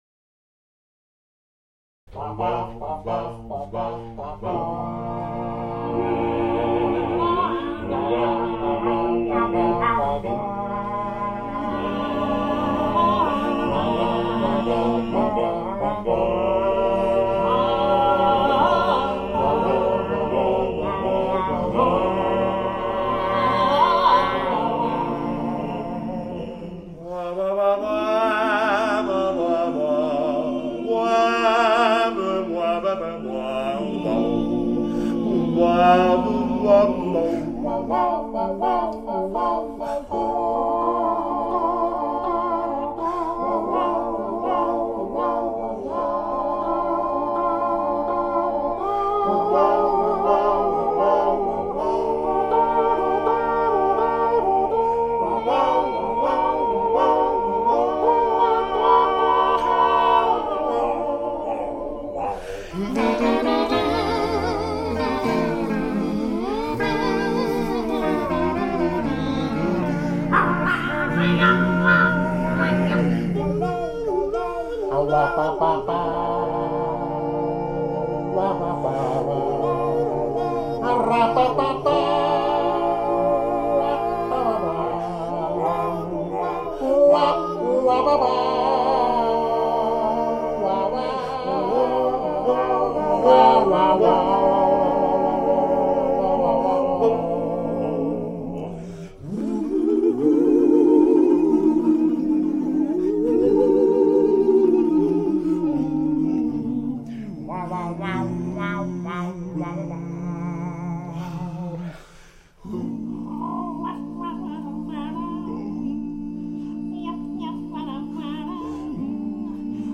The vocal ensemble Hudson Shad is in town to perform the work they know best: Kurt Weill & Bertolt Brecht's Seven Deadly Sins, with Storm Large and the Toledo Symphony (they've performed it more than any other group in history, in fact!).
joins us in the studio to sing some of their favorite songs and share in conversation.